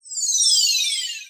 MAGIC_SPELL_Metallic_Rain_Down_Subtle_stereo.wav